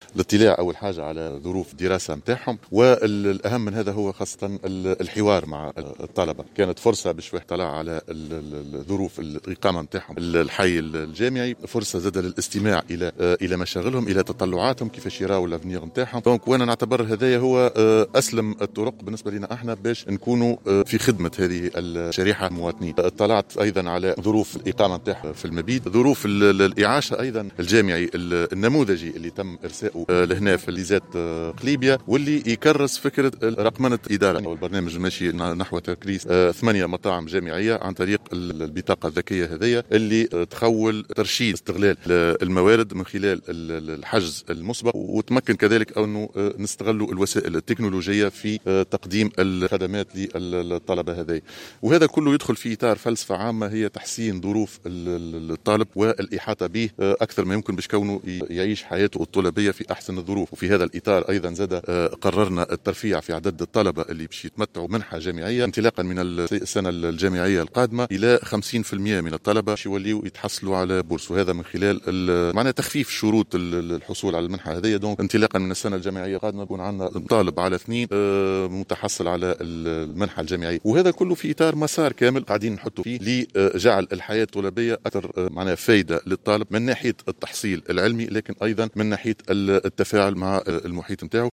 أعلن رئيس الحكومة، هشام المشيشي، على هامش زيارة أداها اليوم إلى المعهد العالي للدراسات التكنولوجية بقليبية، عن الترفيع في عدد الطلبة الذين يتمتعون بالمنحة الجامعية انطلاقا من السنة الجامعية القادمة.